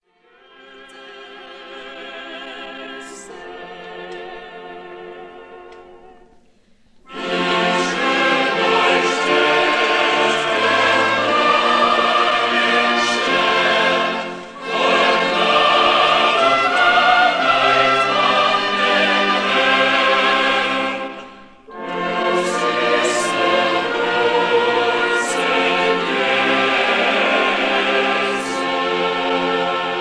and Orchestra